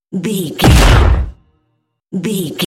Dramatic hit laser shot
Sound Effects
Atonal
heavy
intense
aggressive